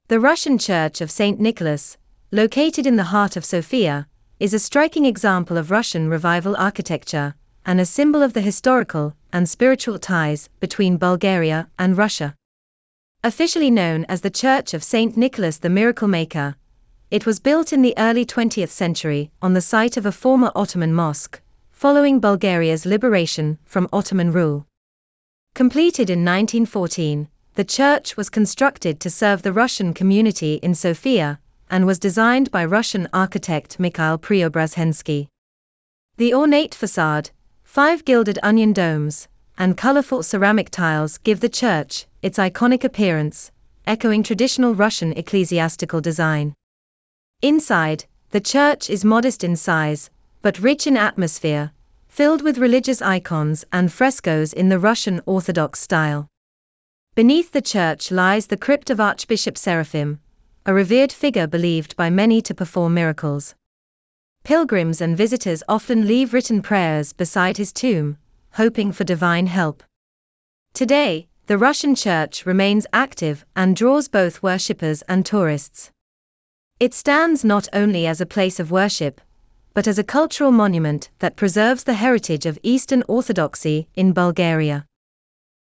Text_to_Speech.wav